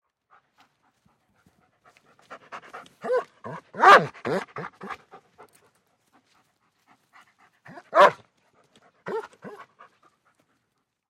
Звуки немецкой овчарки
Радостное дыхание немецкой овчарки при встрече хозяина